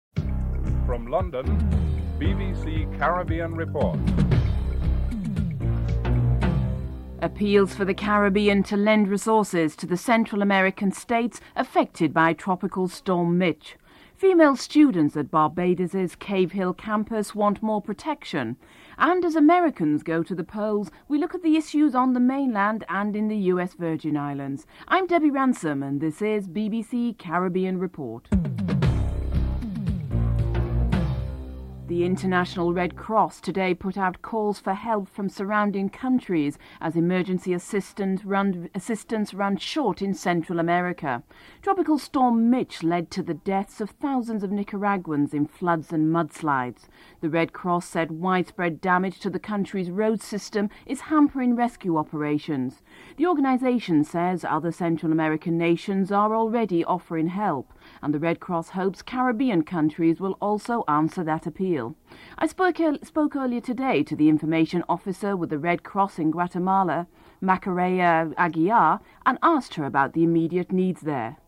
1. Headlines (00:00-00:31)